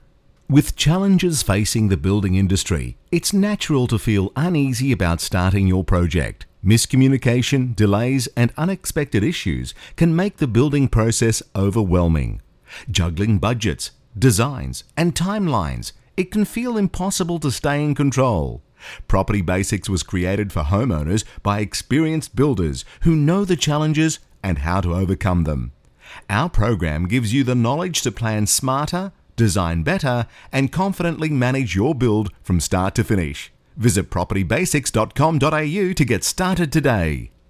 Professionally recorded phone messages
Male and female voice artists have clear enunciation and exceptional voice skills to convey your message in a friendly, pleasant manner.
Male voice - Style 2
Male-voice-style-2.mp3